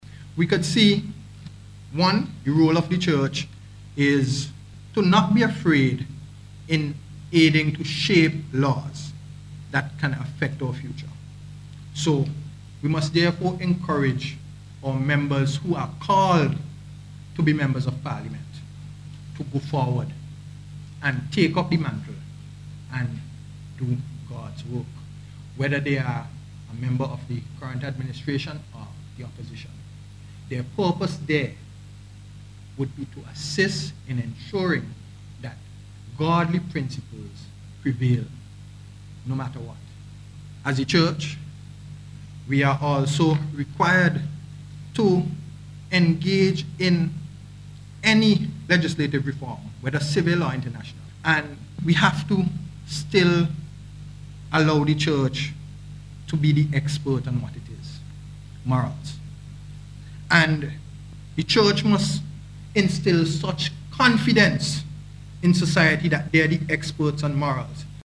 The view was expressed during his presentation of the tenth Annual Archbishop Sir George Cuthbert Manning Woodroffe’s Memorial Lecture last Thursday.